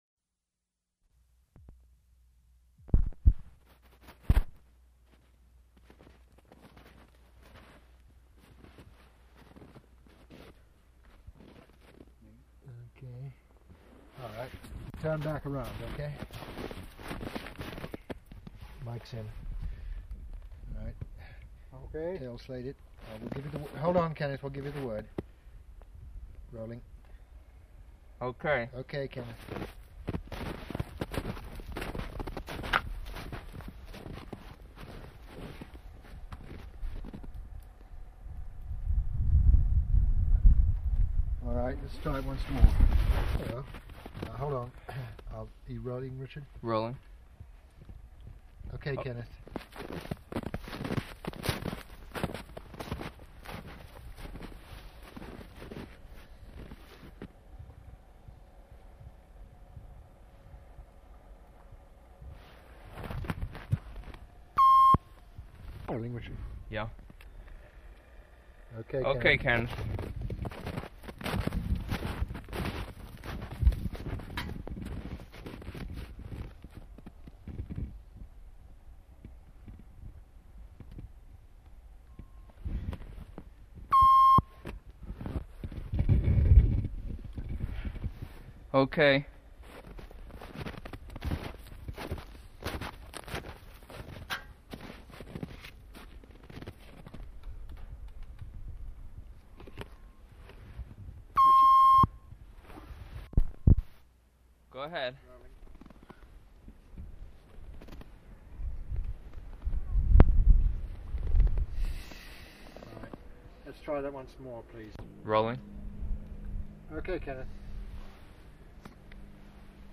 Format 1 sound tape reel (Scotch 3M 208 polyester) : analog ; 7 1/2 ips, full track, mono.
Chelsea (inhabited place) Vermont (state)